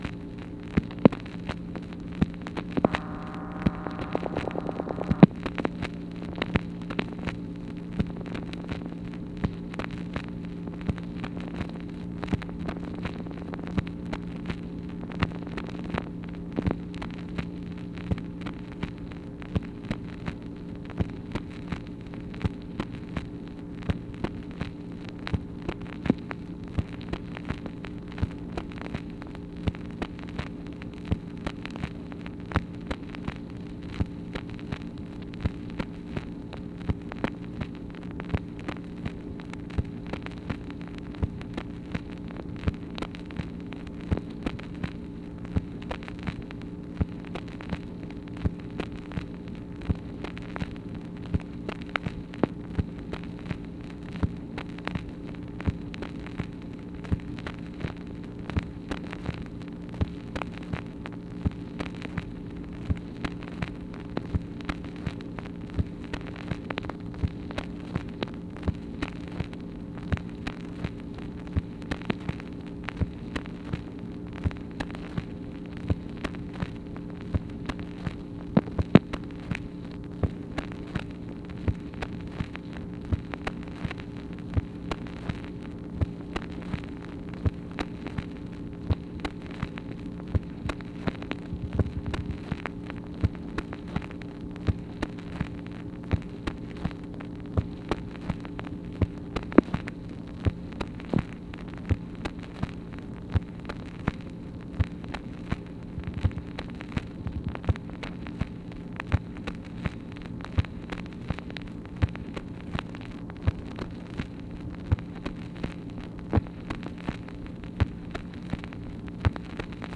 MACHINE NOISE, March 12, 1964
Secret White House Tapes | Lyndon B. Johnson Presidency